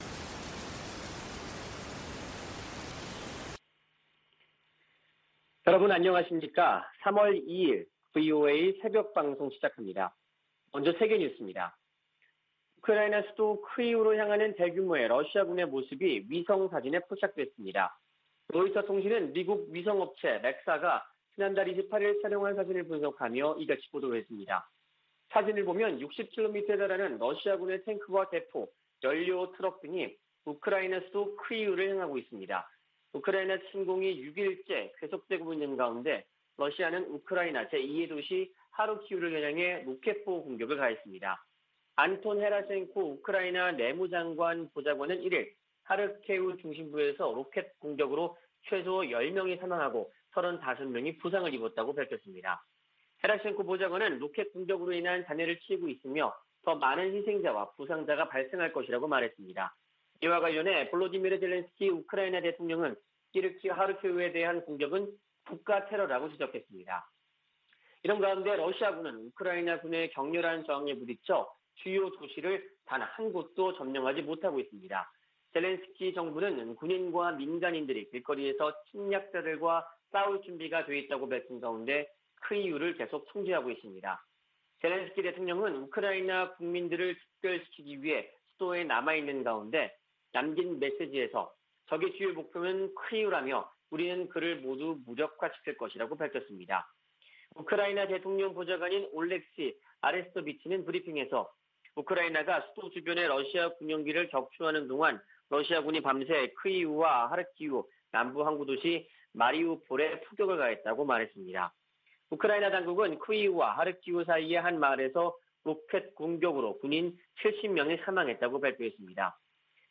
VOA 한국어 '출발 뉴스 쇼', 2022년 3월 2일 방송입니다. 미국, 영국, 일본 등 유엔 안보리 11개국이 북한 탄도미사일 발사를 규탄하고 단호한 제재 이행 방침을 확인했습니다. 괌 당국은 북한의 탄도미사일 시험 발사 재개와 관련해 모든 움직임을 계속 감시하고 있다고 밝혔습니다. 미국의 전문가들은 북한의 최근 미사일 발사가 우주개발을 가장한 탄도미사일 시험일 뿐이라고 지적하고 있습니다.